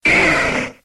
Cri de Caninos dans Pokémon X et Y.